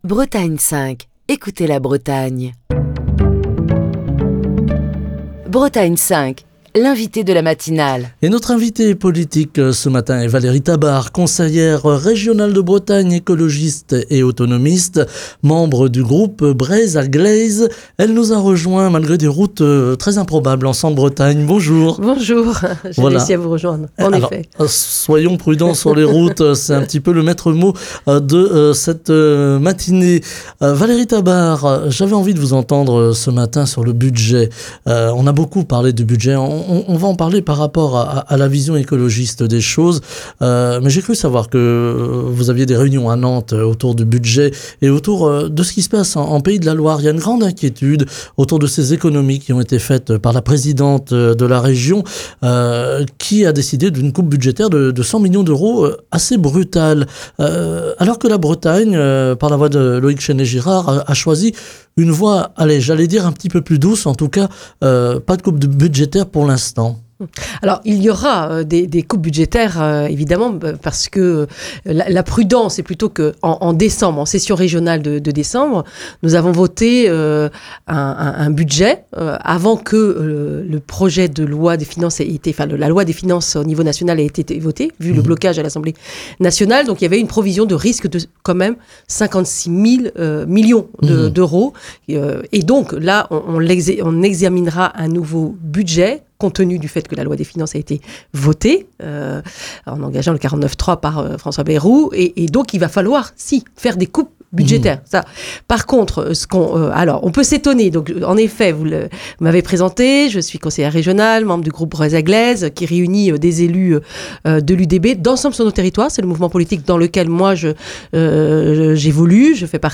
Émission du 11 février 2025. Valérie Tabart, conseillère régionale de Bretagne, écologiste et autonomiste, membre du groupe Breizh a-gleiz et du mouvement Ensemble sur nos territoires, était l'invitée politique de Bretagne 5 Matin ce mardi.